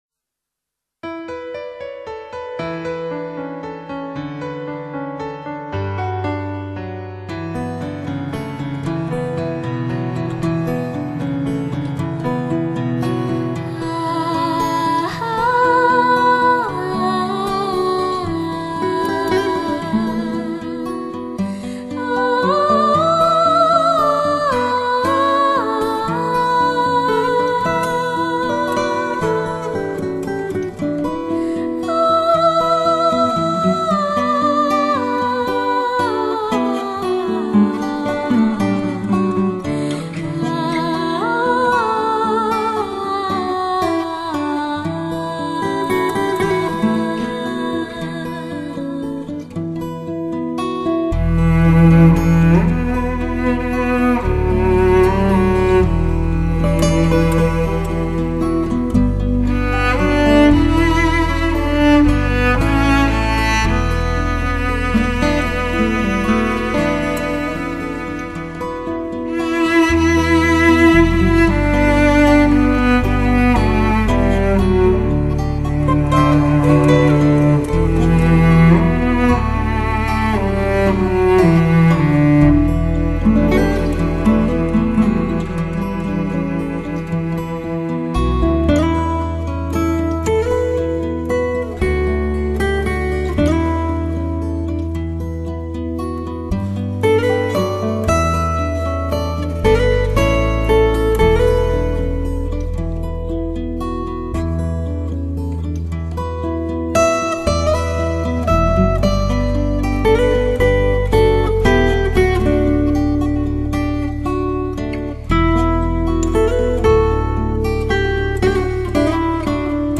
大提琴+女声吟唱